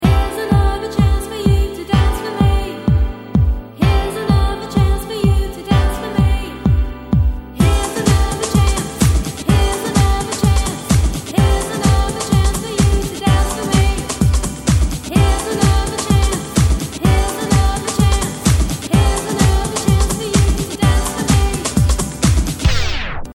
• Category Old Skool